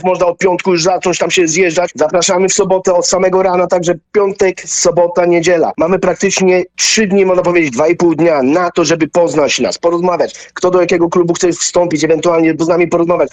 Na antenie Radia Deon Chicago